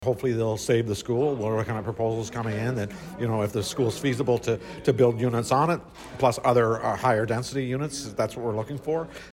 Mayor Ellis says a Request for Proposals would then be put out to the private sector.